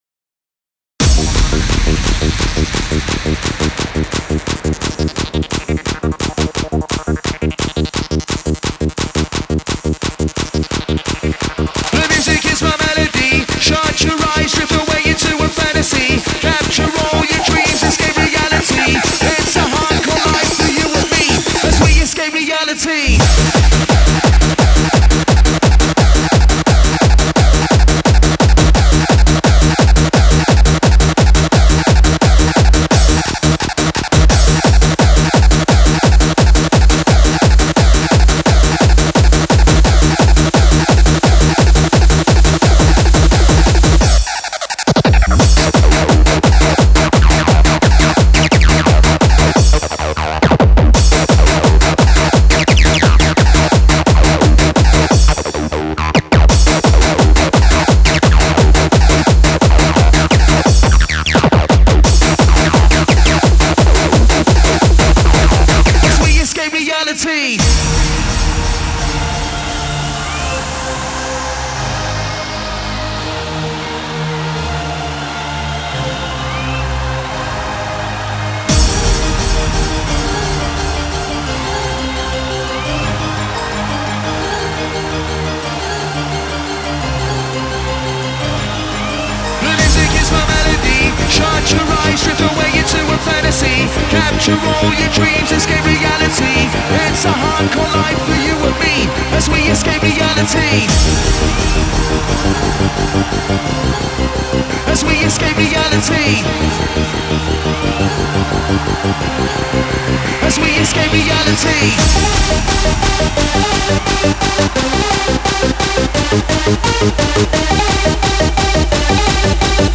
data/music/Japanese/J-Core